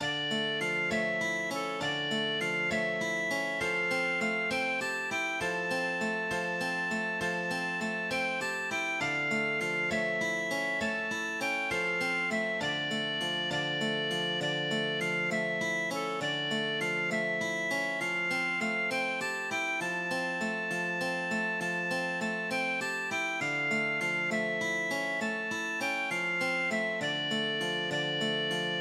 m } \relative { \key c \major \set Staff.midiInstrument="Clarinet" \repeat volta 2 { a'4 c8 b4 e,8 a4 c8 b4. c4 c8 d8 e8
Blut- schwes- ter und To- ten- tanz, hei- ßen ih- re Klin- gen. } \relative a, { \key c \major \clef bass \set Staff.midiInstrument="acoustic guitar (steel)" \repeat volta 2 { a8 e'8 c8 e8 b'8 gis8 a,8 e'8 c8 e8 b'8 gis8 c,8 g'8 e8 g8 d'8 b8 c,8 g'8 e8 c8 g'8 e8 c8 g'8 e8 g8 d'8 b8 a,8 e'8 c8 e8 b'8 gis8 e8 b'8 gis8 c,8 g'8 e8 a,8 e'8 c8 a8 e'8 c8 } } >> } \score { \transpose a d' { \myMusic } \layout { } } \markup { \fill-line { \hspace #1 \column { \line {\bold {II.} } \line {Von vier Mannen, rot und gold,} \line {(O Garetia!)} \line {Bürger Gareths, stark und hold,} \line {nimmer sie heim kamen:} \line {Eboreus, Emmeran} \line {(O Garetia!)} \line {Vitubus und Lucian,} \line {waren ihre Namen.} \vspace #1 \line {\bold {III.} } \line {Von vier Maiden, rot und gold,} \line {(O Garetia!)} \line {Bürger Gareths, stark und hold,} \line {möchte ich nun singen:} \line {Schwanenfeder, Ogerstark,} \line {(O Garetia!)} \line {Schwingenrauschen, Feuerschlag} \line {heißen ihre Klingen.} } \hspace #2 \column { \line {\bold {IV.} } \line {Von vier Maiden, rot und gold,} \line {(O Garetia!)} \line {Bürger Gareths, stark und hold,} \line {nimmer sie heim kamen:} \line {Palinai, Duridanya,} \line {(O Garetia!)} \line {Yasinthe und Celissa} \line {waren ihre Namen.} \vspace #1 \line {\bold {V.} } \line {Von acht Helden, rot und gold,} \line {(O Garetia!)} \line {Bürger Gareths, stark und hold,} \line {möchte ich nun singen:} \line {Auf dem Weg nach Bosparan} \line {(O Garetia!)} \line {Horas bittend stolz voran,} \line {in den Tod sie gingen.} } \hspace #1 } } \score { \transpose a d' { \unfoldRepeats { \myMusic } } \midi { } }